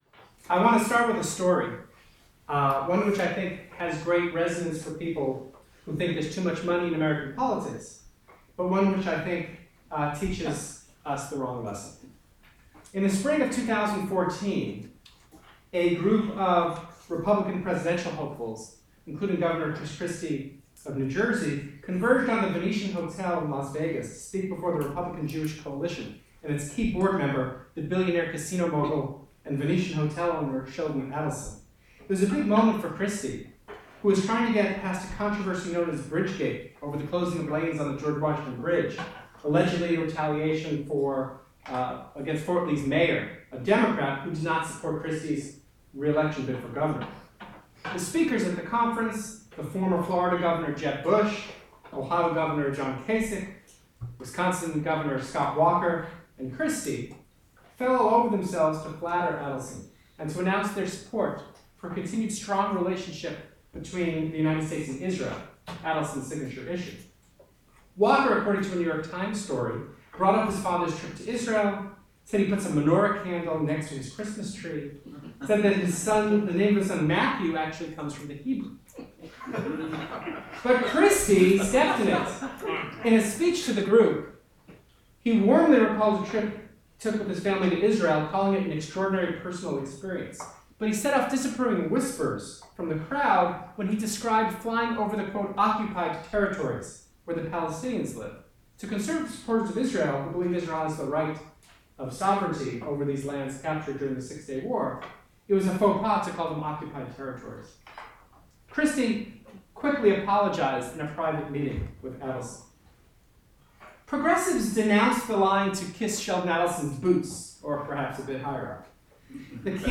Hasen delivers the annual 2016 Constitution Day lecture on campaign money, the Supreme Court, and the distortion of American elections.